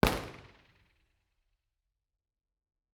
IR_EigenmikeHHL1_processed_Bformat.wav